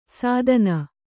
♪ sādana